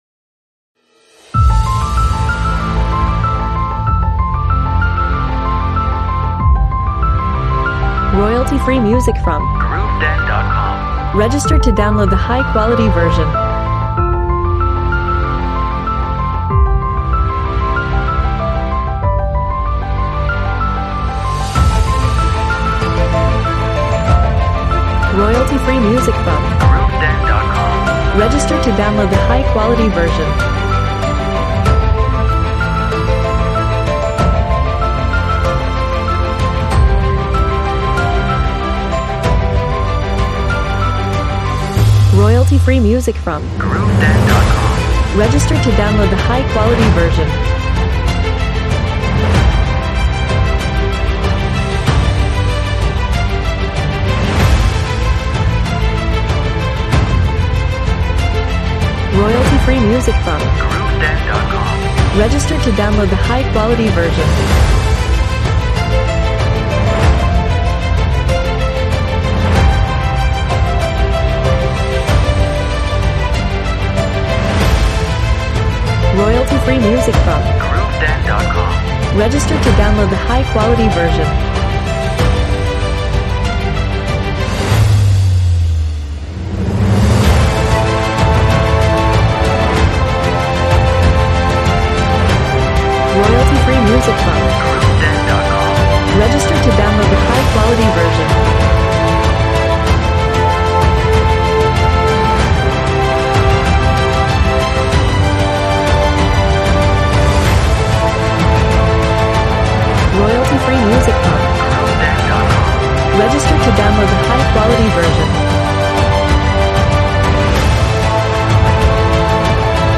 Cinematic music, with a dramatic piano and strings.